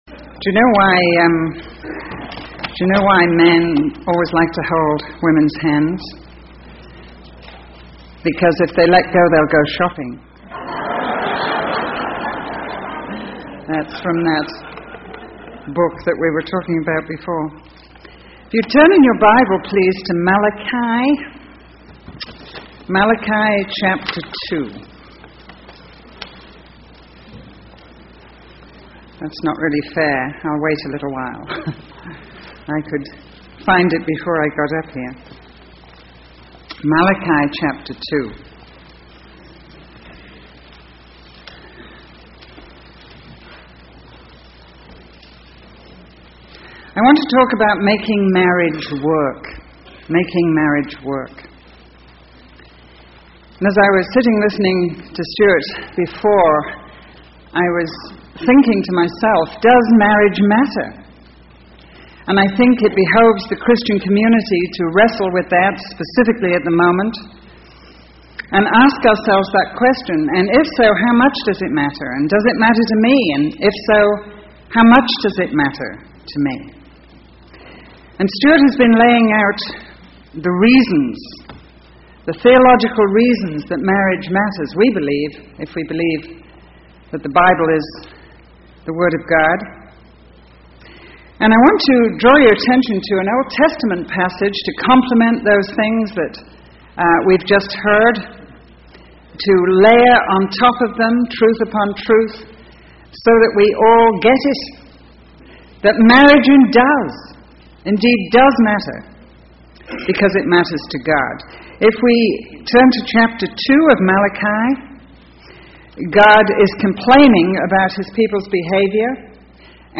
In this sermon, the speaker emphasizes the importance of calling things by their real names and knowing oneself when faced with temptation. He encourages building accountability and support systems to resist temptation and listening to God's guidance. The speaker references the story of Nathan confronting David to illustrate how God can speak to individuals through others.